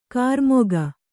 ♪ kārmoga